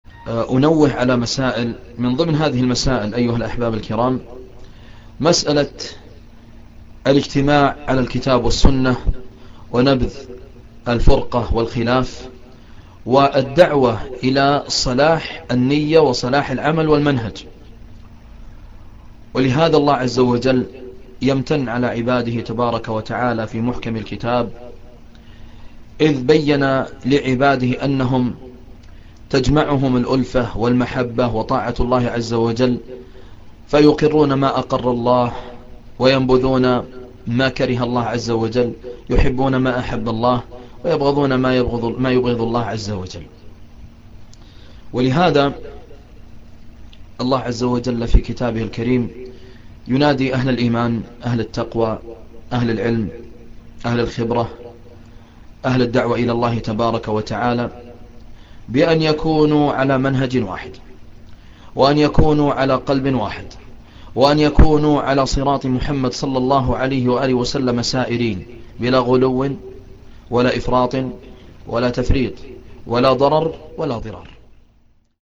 من هو هذا المحاضر :